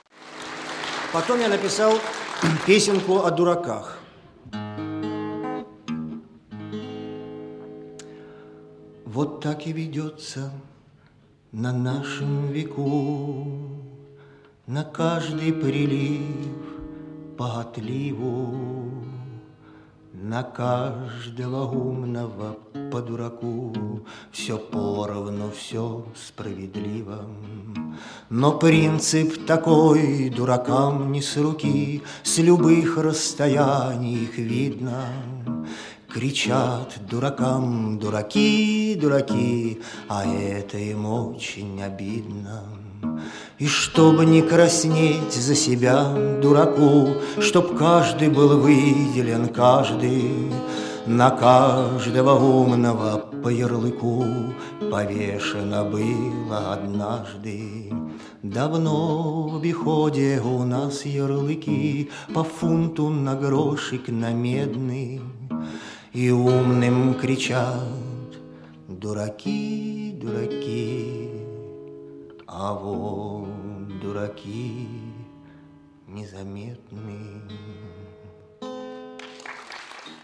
Запись с концерта в Штутгарте. 1987 г. Музыка